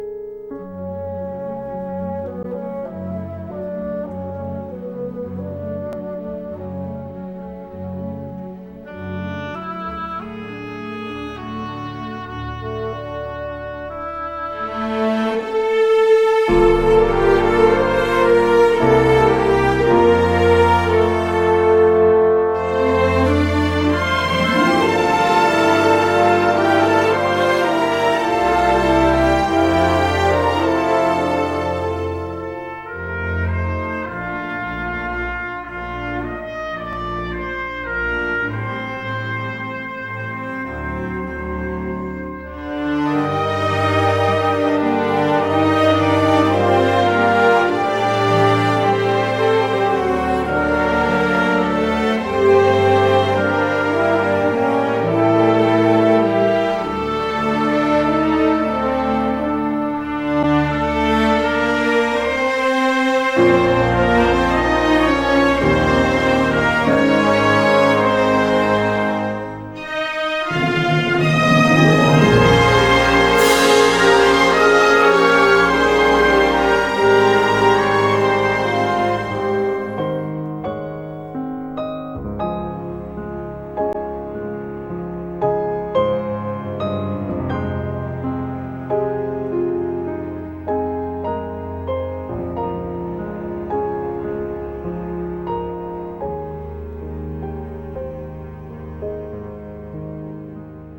suntuosa partitura